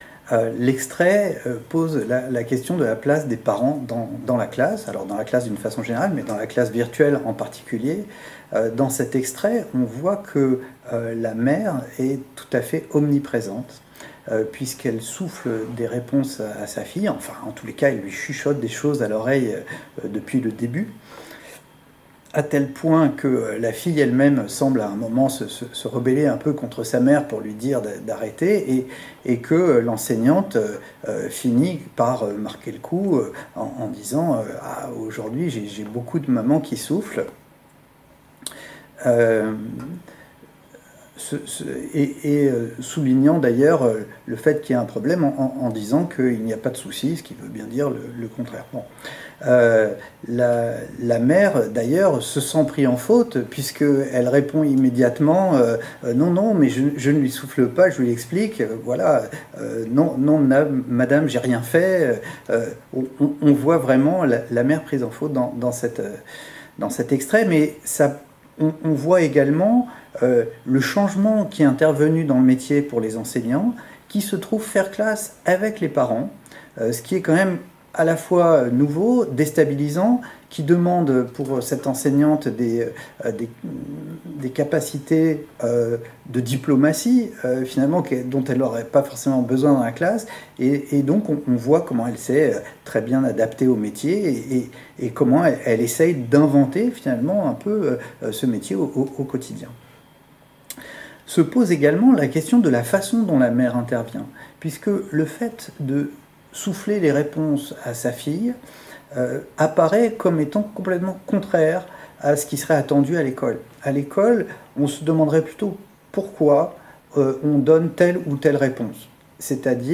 Discussion entre enseignants